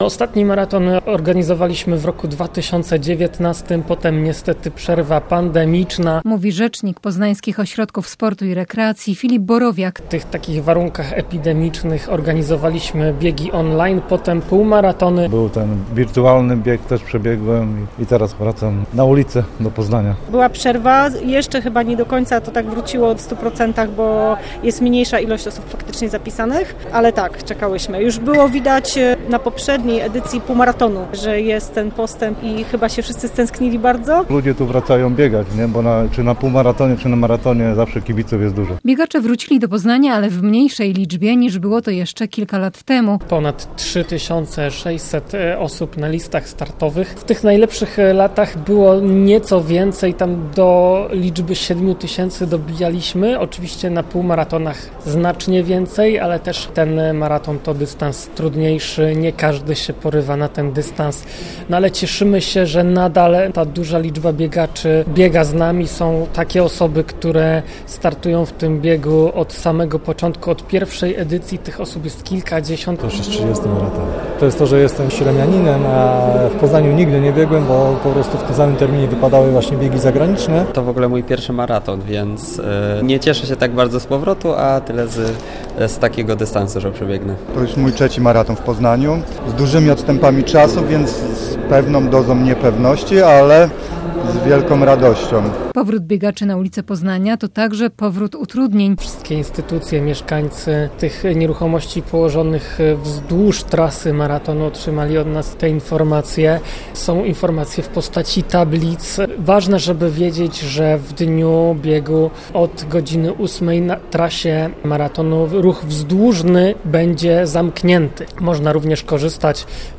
- mówią wystawcy.
- mówią biegacze.